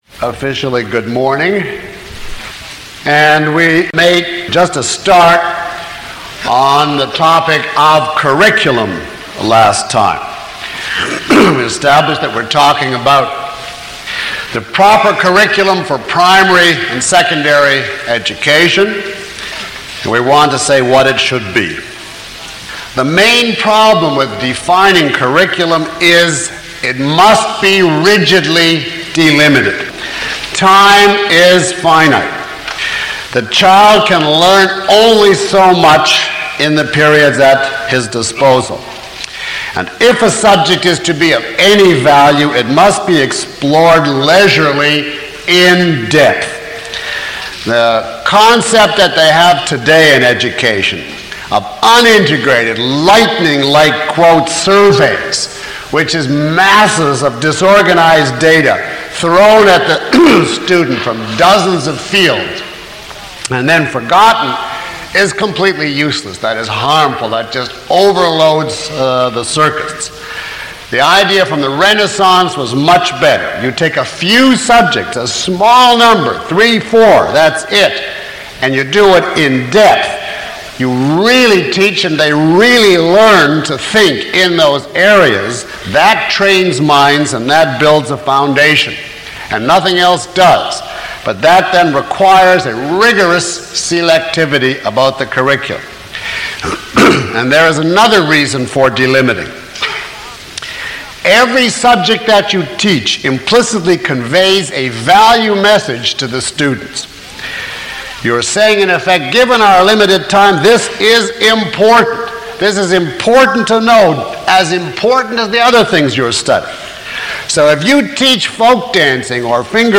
Download Full Course Study Guide (PDF) Q&A Guide Below is a list of questions from the audience taken from this lecture, along with (approximate) time stamps. 1:09:38 You quoted The Art of Teaching .
Lecture 04 - Philosophy of Education.mp3